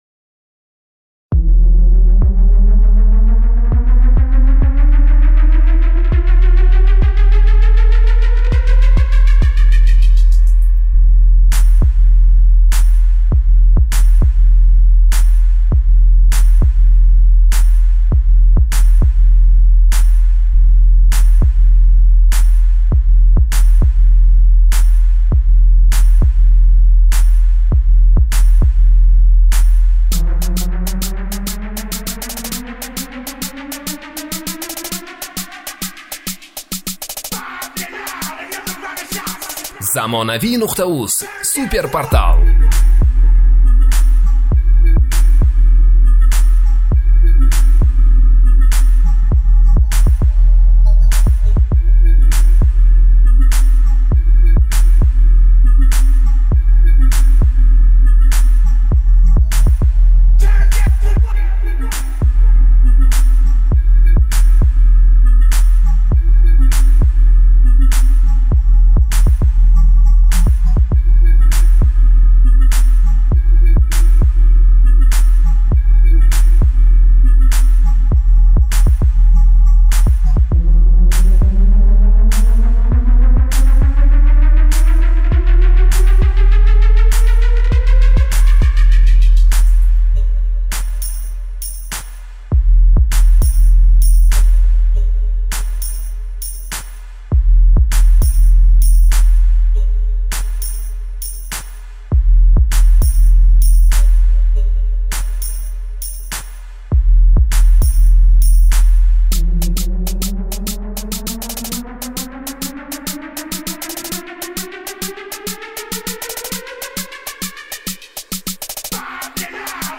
Mashina BASS MP3 Мне нравится!